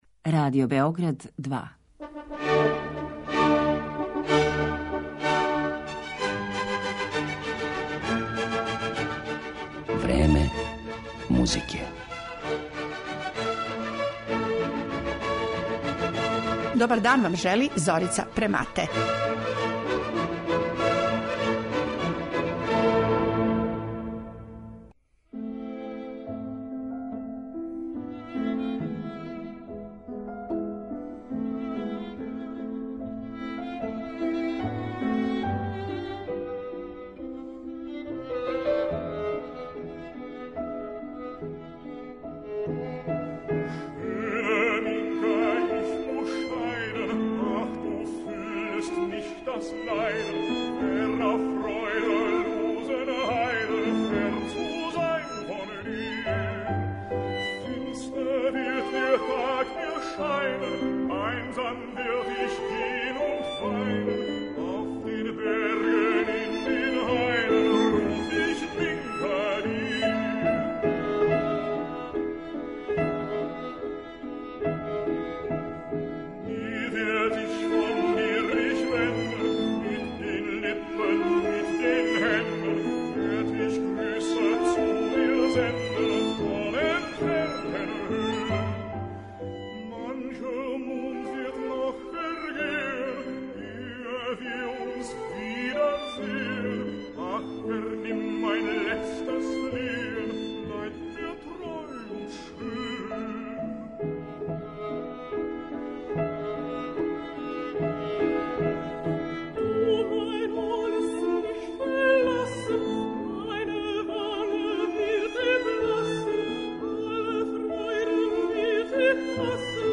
Емисија Време музике посвећена је једној украјинској народној песми која је позната и код нас јер су је, после Октобарске револуције, у наше крајеве донели козаци. Неколико композитора епохе класицизма и романтизма приметило је и обрадило песму „Лепа Минка", а ви ћете слушати како су то учинили Бетовен, Вебер, Доницети и Хумел.